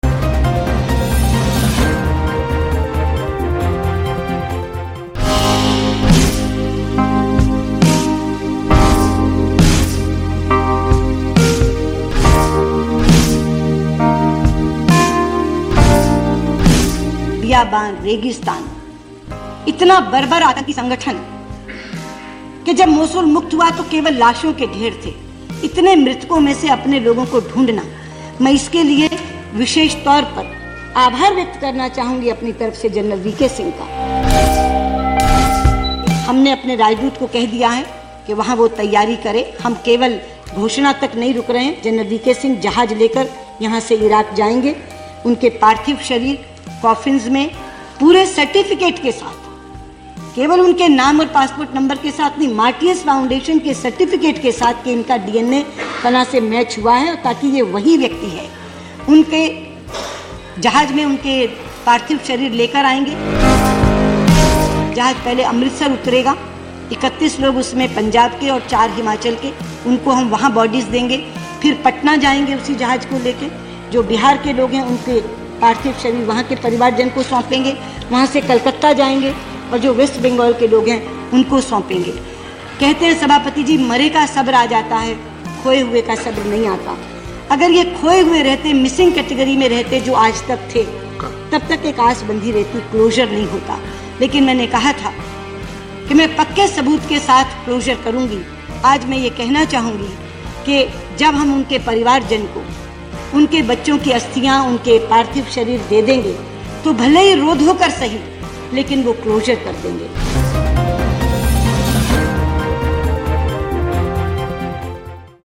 News Report / 39 भारतीयों को ISIS उतरा मौत के घाट, सुषमा स्वराज का राज्यसभा में बयान